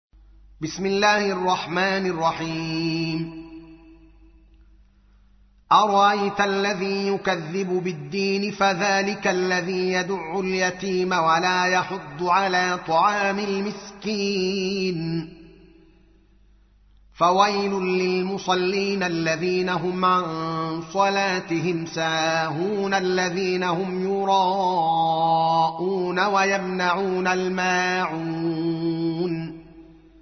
تحميل : 107. سورة الماعون / القارئ الدوكالي محمد العالم / القرآن الكريم / موقع يا حسين